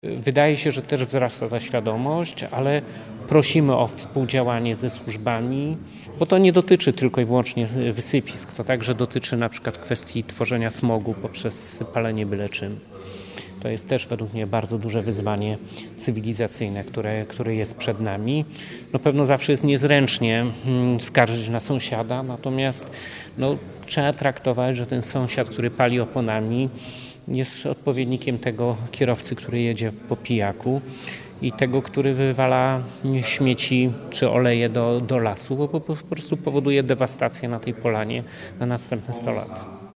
– Apeluje o świadomość społeczność – dodał Jarosław Obremski.